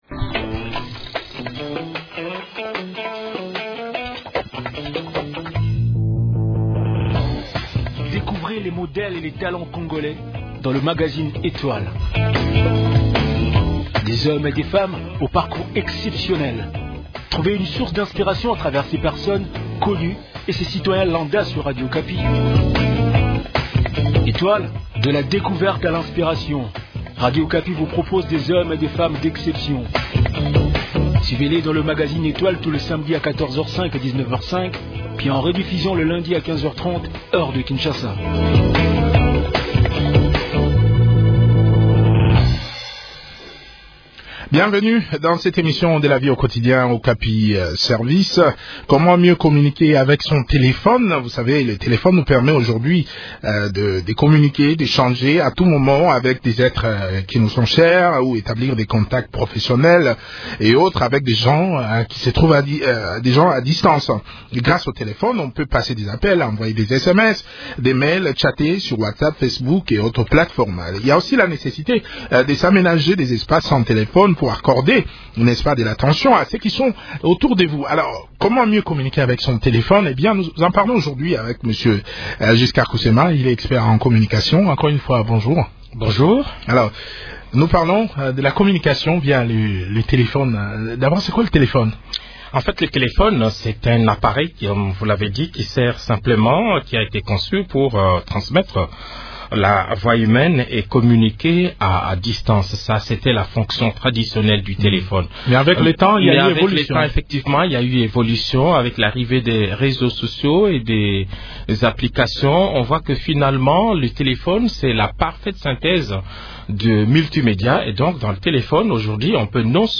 chercheur en Sciences de l’Information et de la Communication.